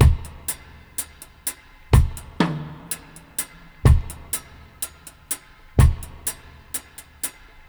62-FX-04.wav